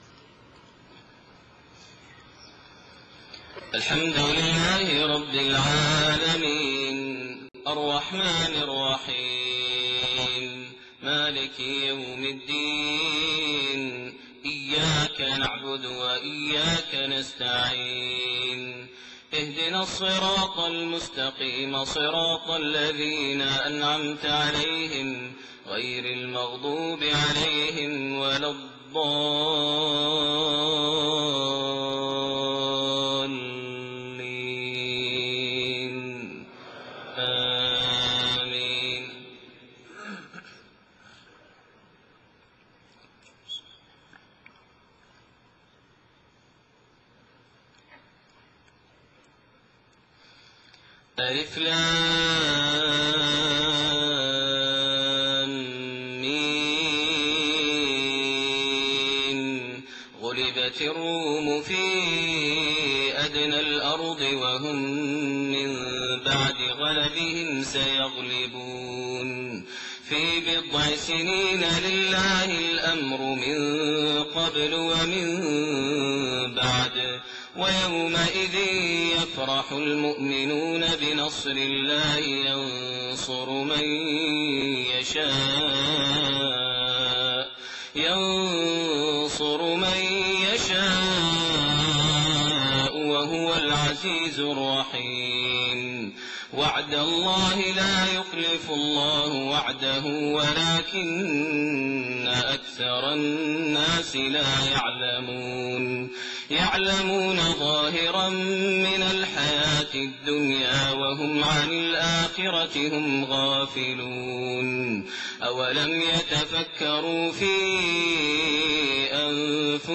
Fajr prayer from Surah Ar-Room > 1429 H > Prayers - Maher Almuaiqly Recitations